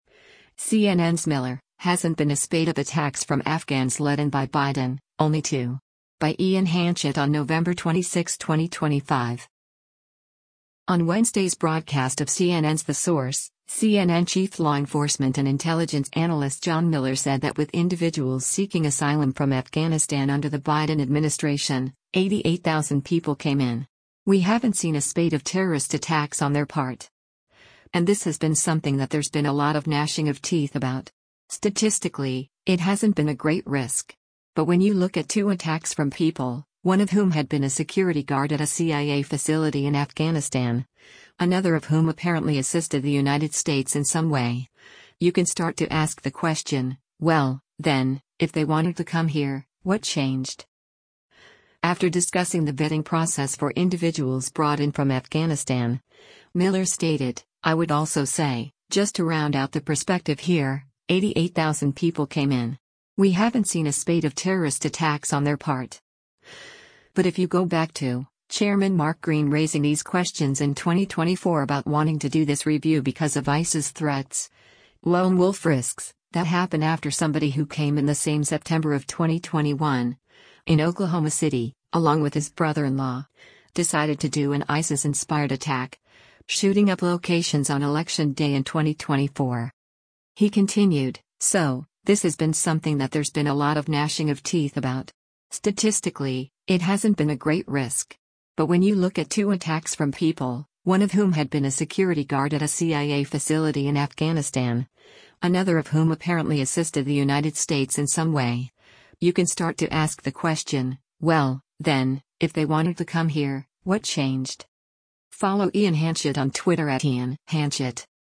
On Wednesday’s broadcast of CNN’s “The Source,” CNN Chief Law Enforcement and Intelligence Analyst John Miller said that with individuals seeking asylum from Afghanistan under the Biden administration, “88,000 people came in. We haven’t seen a spate of terrorist attacks on their part.”